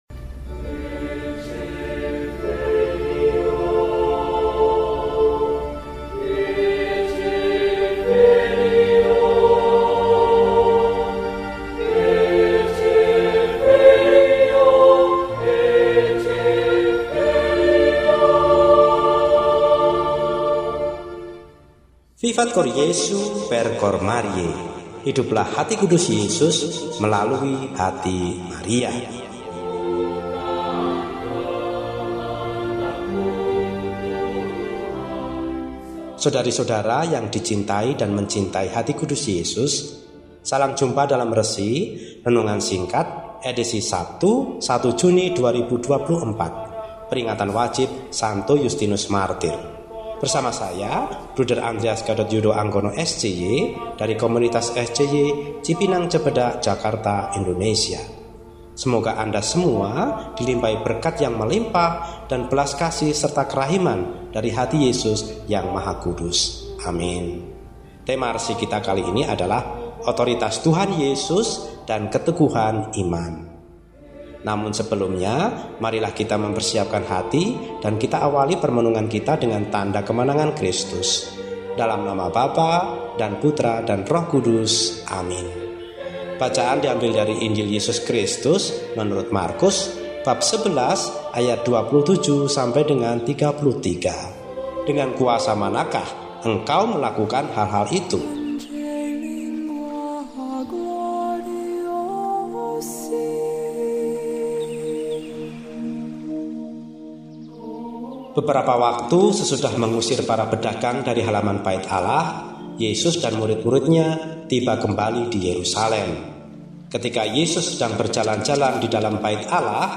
Sabtu, 01 Juni 2024 – Peringatan Wajib St. Yustinus, Martir – RESI (Renungan Singkat) DEHONIAN